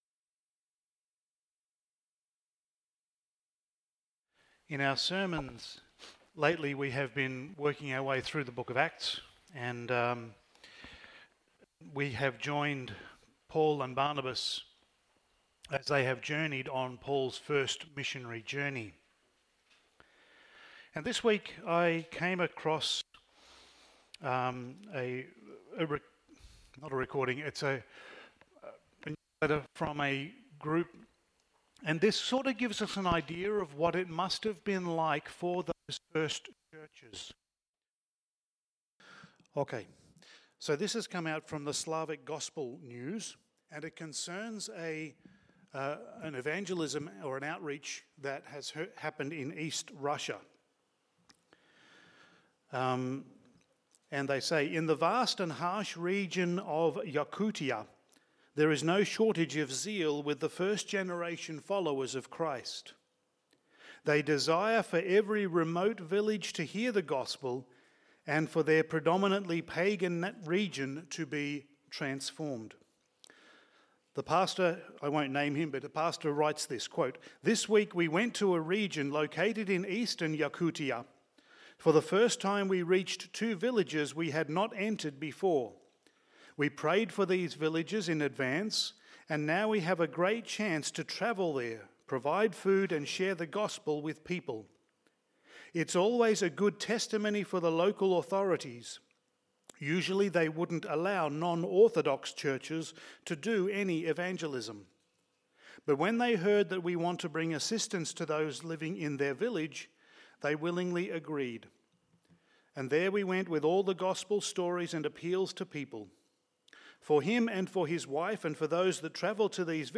Passage: Acts 14:20-28 Service Type: Sunday Morning